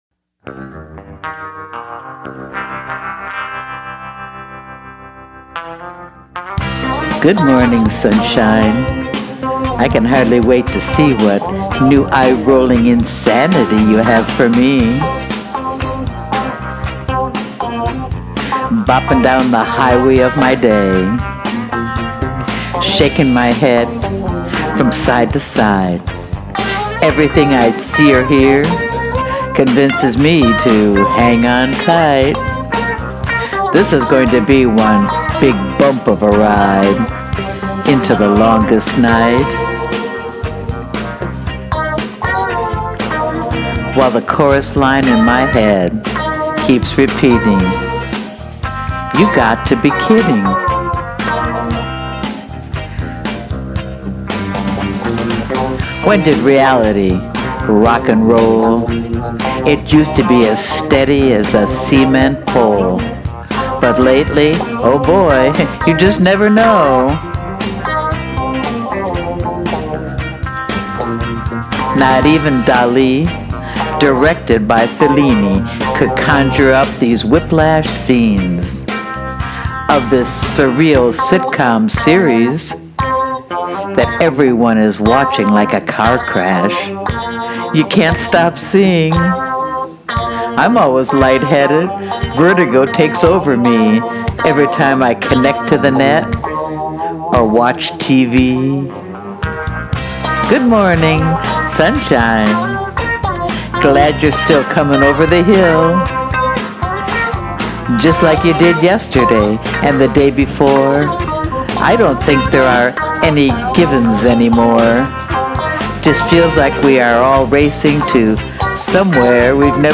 who has has never lost his wicked guitar
Great music and vocals.